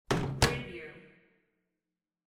Microwave Oven Door Close Wav Sound
Description: The sound of a microwave oven door being closed
Properties: 48.000 kHz 16-bit Stereo
Keywords: microwave, oven, door, shut, shutting, close, closed, closing
microwave-oven-door-close-preview-1.mp3